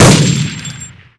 sol_reklam_link sag_reklam_link Warrock Oyun Dosyalar� Ana Sayfa > Sound > Weapons > Cal_50 Dosya Ad� Boyutu Son D�zenleme ..
WR_fire.wav